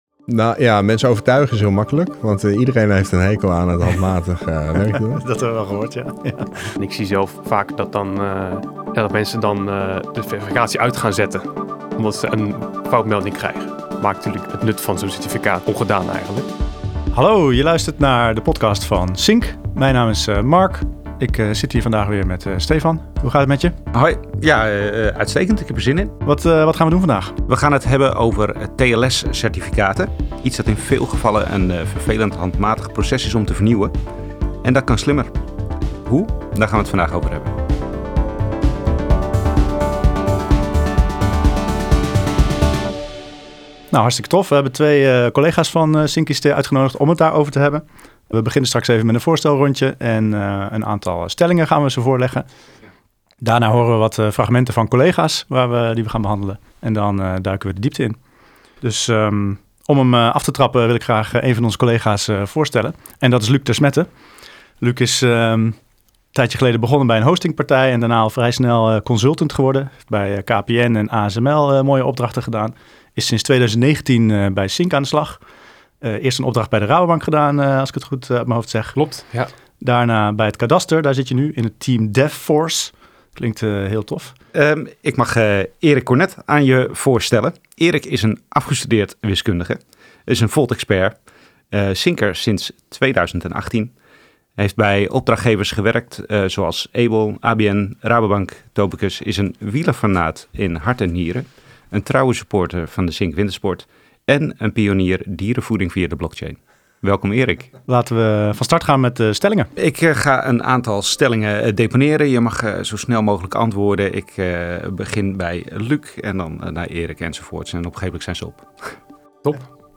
Iedere aflevering zijn er twee specialisten van CINQ te gast, en zij spreken met elkaar over tooling, slimme technische oplossingen en hun vakgebied in het algemeen.